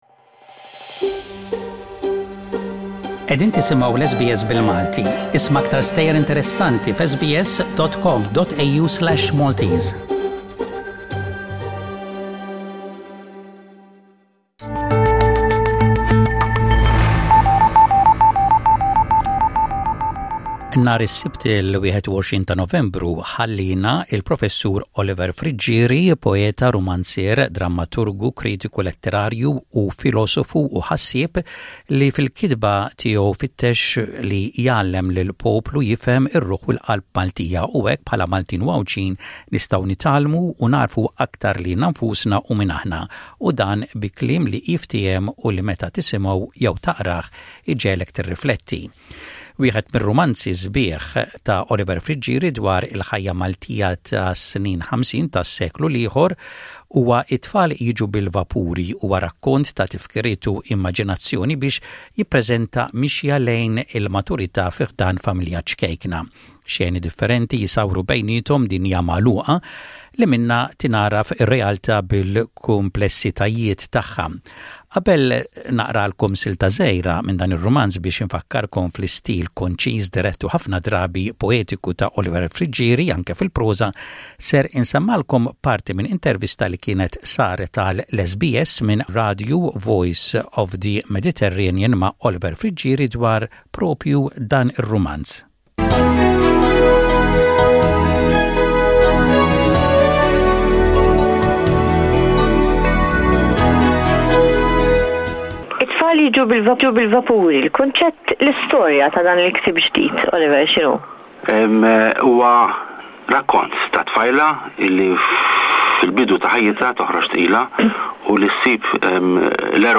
It-Tfal Jiġu bil-Vapuri (Children Come by Ship) is a devastating novel set in the 1930s that attempts to discover the roots of a long-gone social and religious interrelationship on the Southern Mediterranean island of Malta, a former British colony. This is an interview from 2001 in which Oliver Friggieri talks about the main characters and the complexity of this novel.